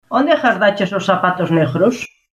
-Gheada: fenómeno que consiste na pronuncia aspirada do /g/ en calquera posición:ghato, algho...